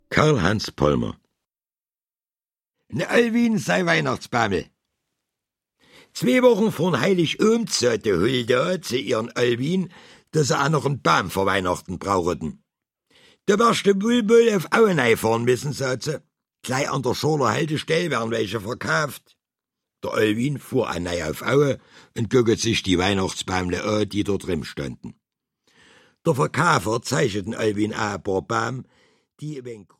Und weil die meisten mündlich überliefert wurden, sind es Mundart-Geschichten auf „Arzgebirgisch“.
Deutsch - Mundart